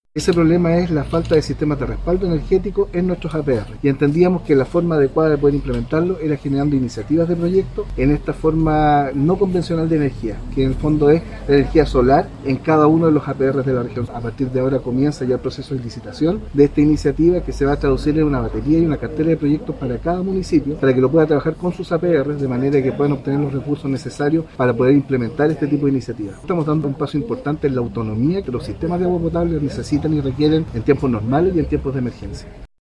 La presentación de la iniciativa se efectuó en la sede del Comité de Agua Potable Rural de La Palma, en Quillota y fue encabezada por el presidente de la Asociación Regional de Municipalidades y alcalde de Limache, Daniel Morales Espíndola.